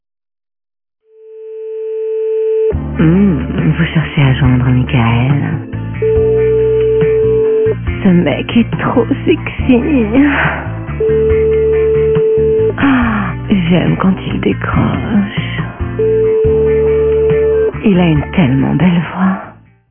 - Personnalisez votre tonalité d’attente et faites patienter vos correspondants autrement ! -